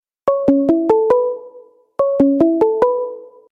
Category Sound Effects